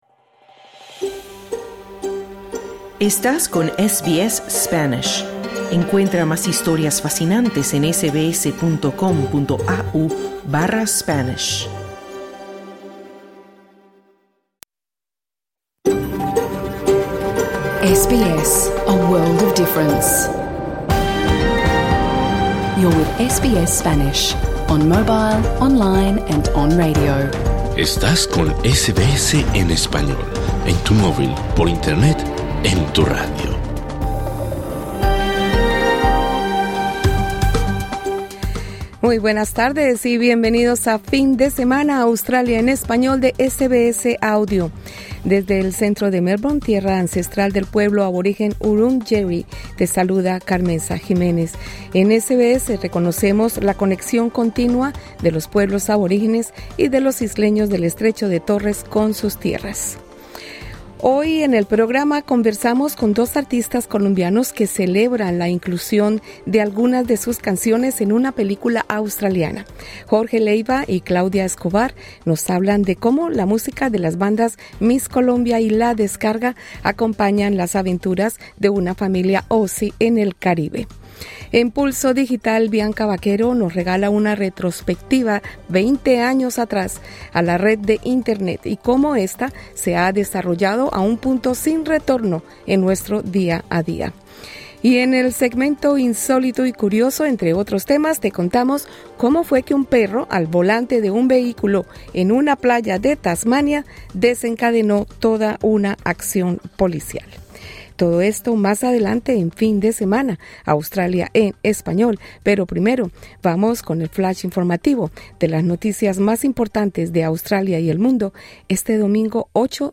Los líderes del Partido Liberal y del Partido Nacional han anunciado un acuerdo de reunificación. Escucha esta noticia y además la entrevista a dos artistas colombianos residentes en Melbourne que nos hablan de cómo su música hace parte de una reciente producción cinematográfica australiana.